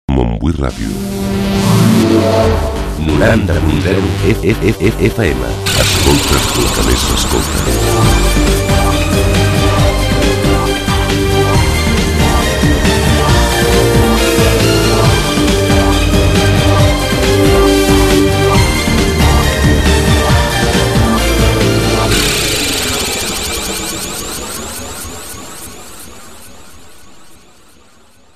Identificació de l'emissora